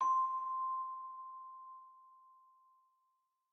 celesta1_2.ogg